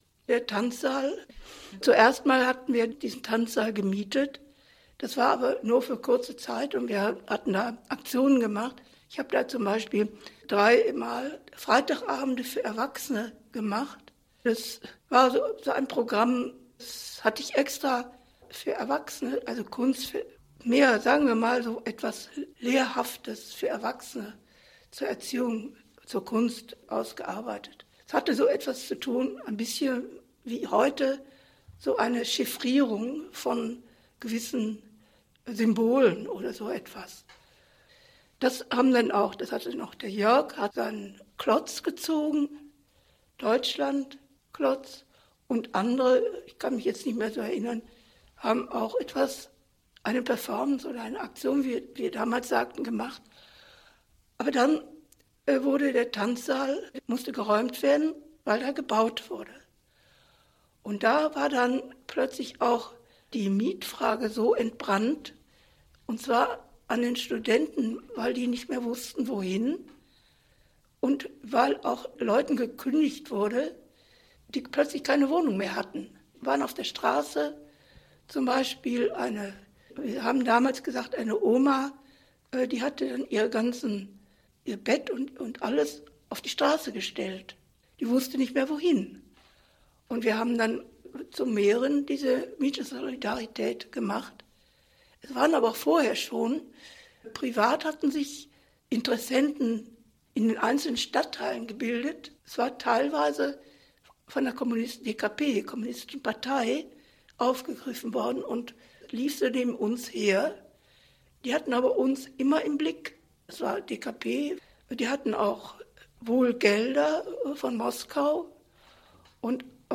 Interview Audioarchiv Kunst: